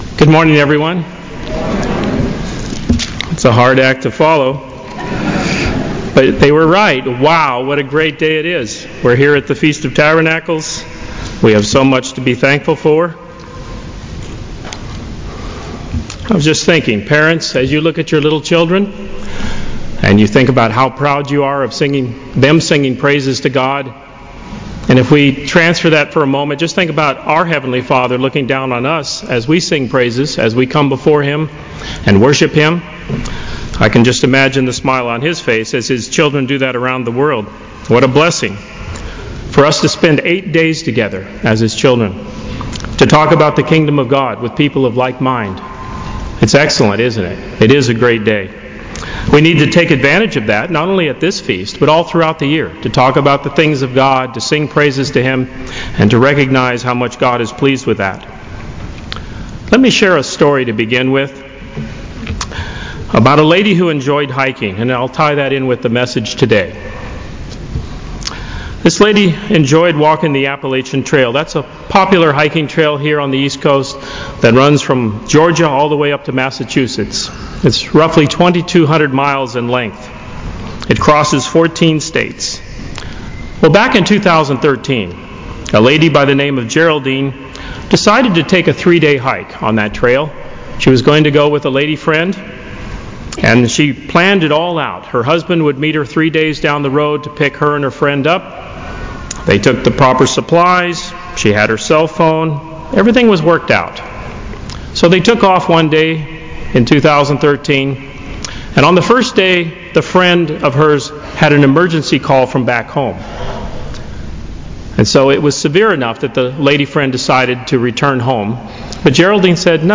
This sermon was given at the Jekyll Island, Georgia 2017 Feast site.